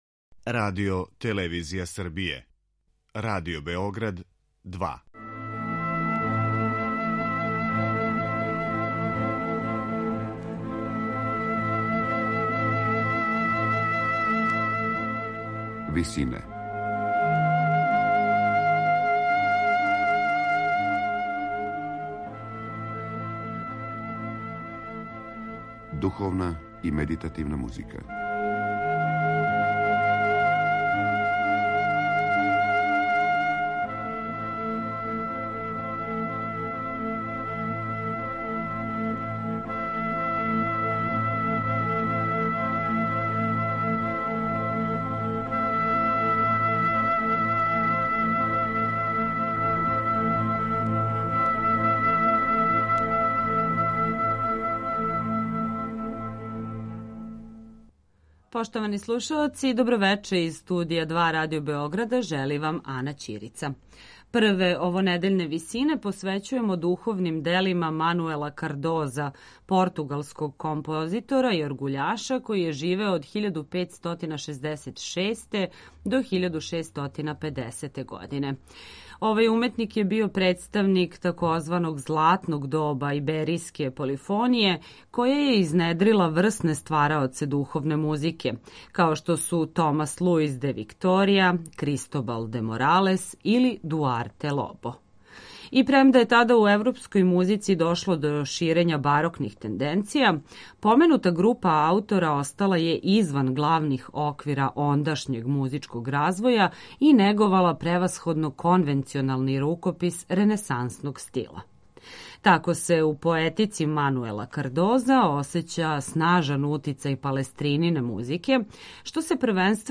Духовна дела